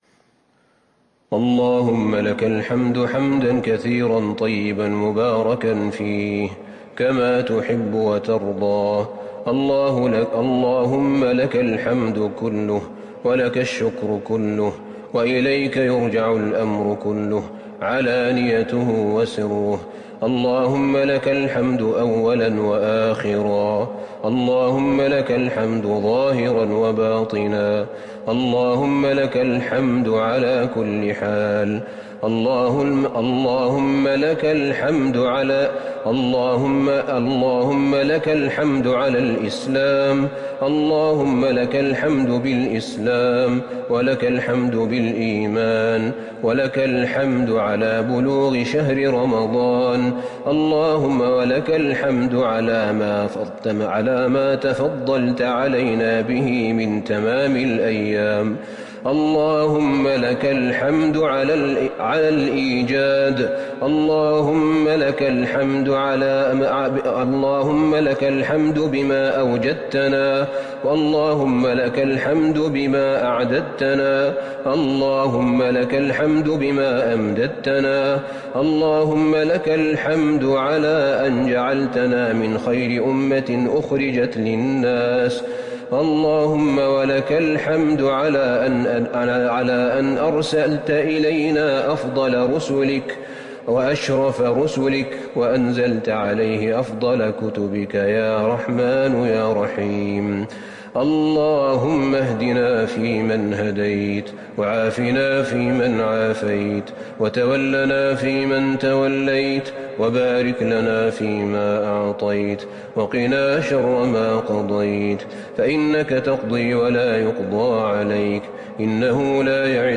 دعاء القنوت ليلة 30 رمضان 1441هـ > تراويح الحرم النبوي عام 1441 🕌 > التراويح - تلاوات الحرمين